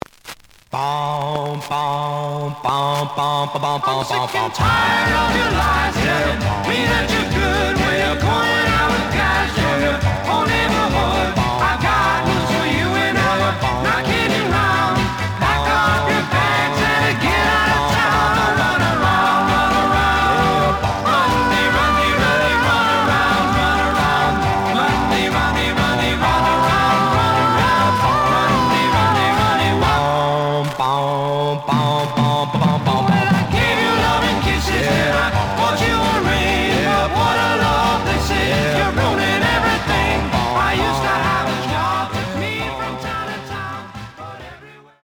The audio sample is recorded from the actual item.
●Genre: Rhythm And Blues / Rock 'n' Roll
Noticeabole noise on parts of A side.